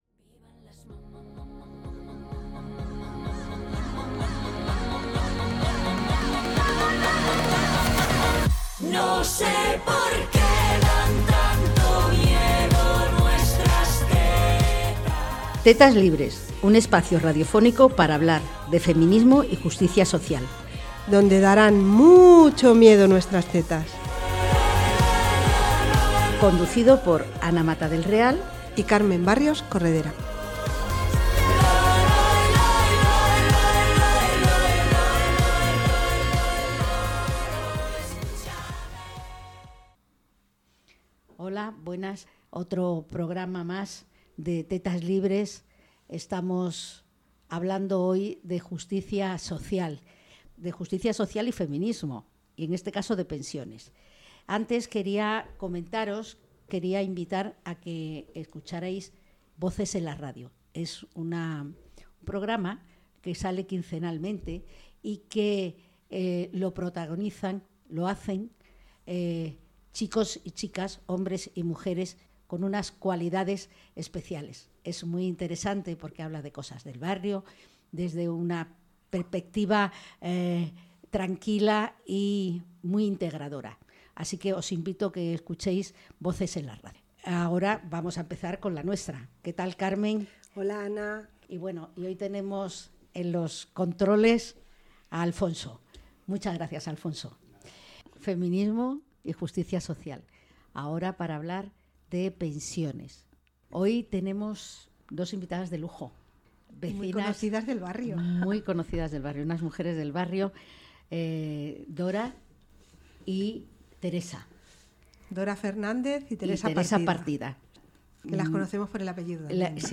Tetas Libres inaugura su Segunda Temporada con una entrevista